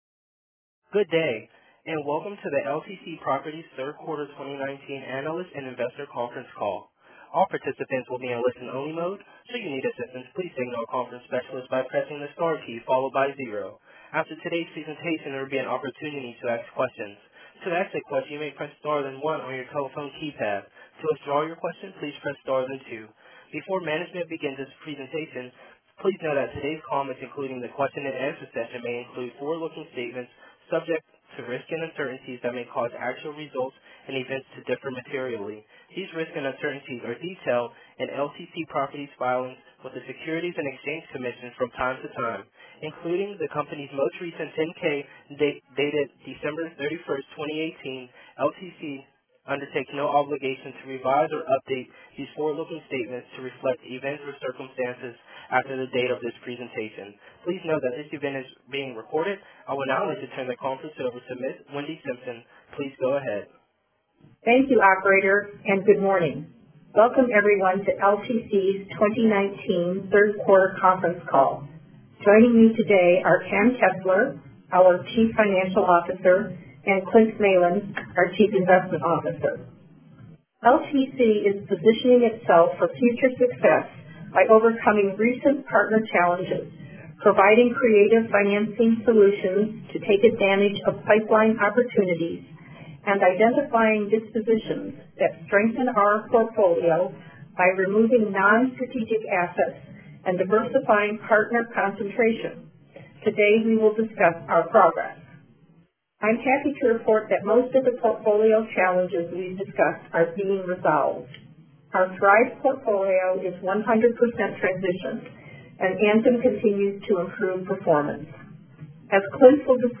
Audio-Replay-of-LTC-Properties-Inc-Q3-2019-Earnings-Call.mp3